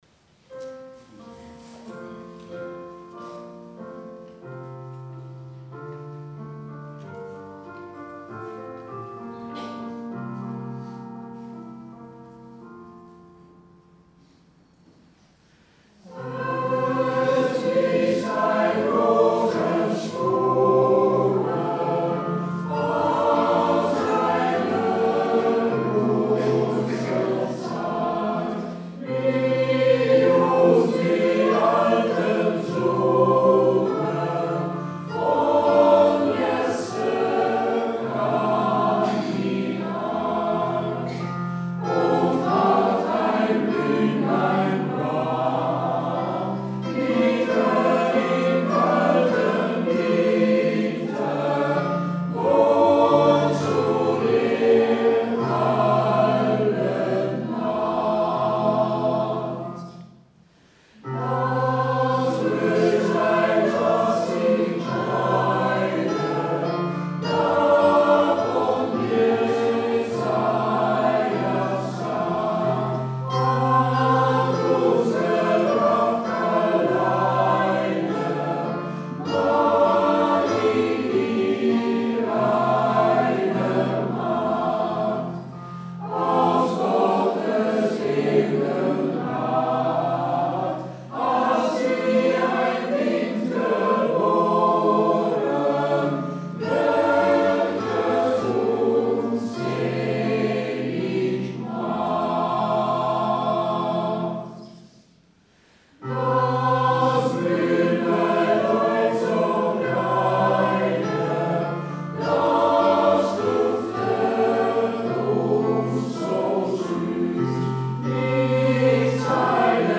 sKerstconcert in de kapel van het RK Kerkhof Groningen,  20 december 2014